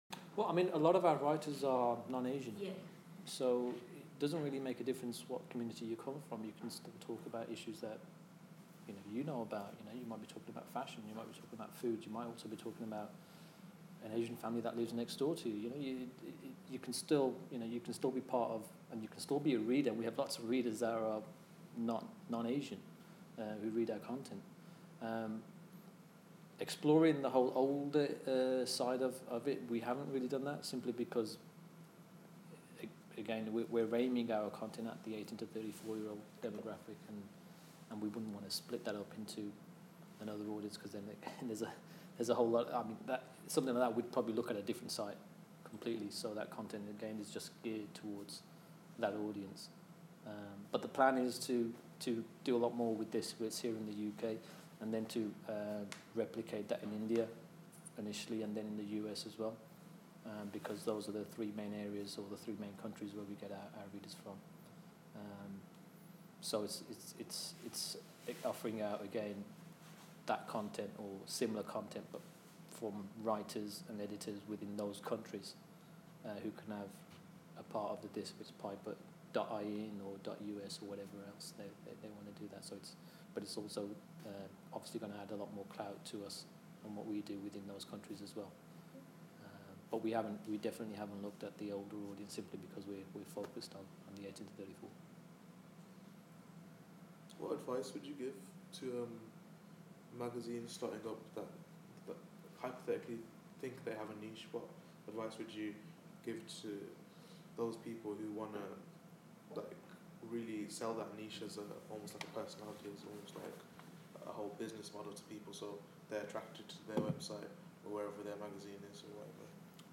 speaks to students at Birmingham City University about why they're not writing for an older audience, and plans to expand to the US and India. 2'00: Advice for launching a new magazine? 5'50: What factors have led to the website relaunch?